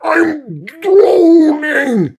goatfree05.ogg